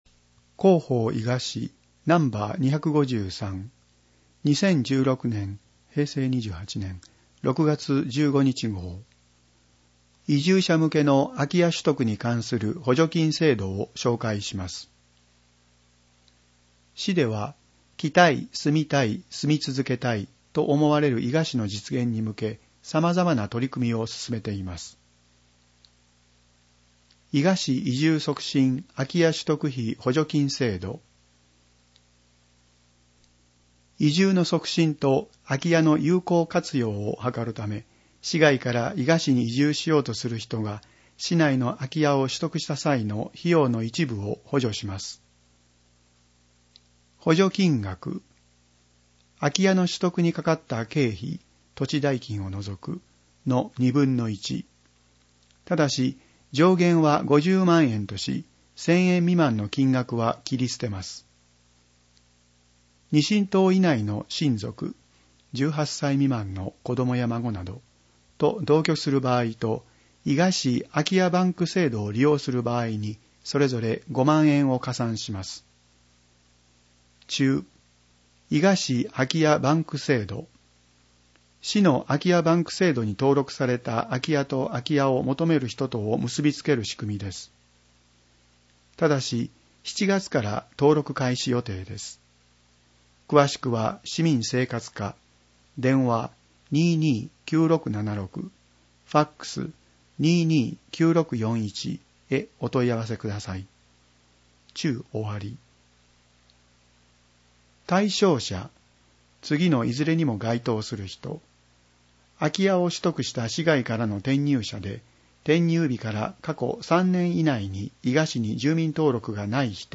※文字を読むことが困難な視覚障がい者や高齢者のために、ホームページ上で広報いが市「音声版」を公開しています。